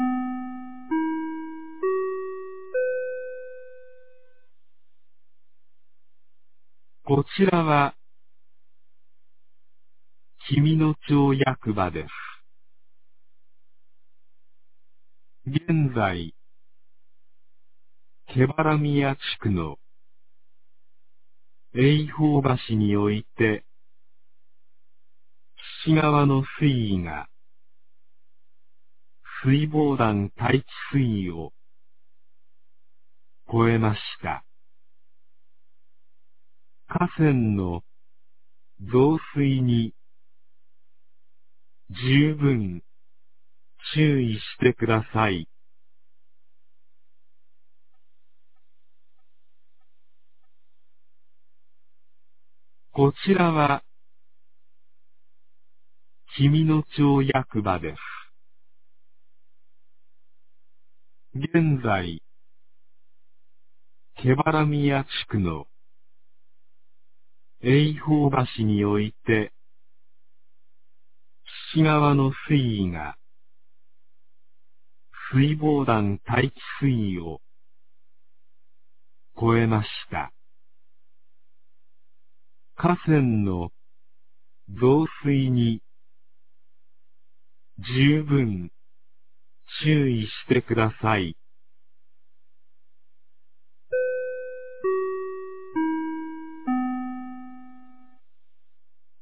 2023年06月02日 11時31分に、紀美野町より全地区へ放送がありました。
放送音声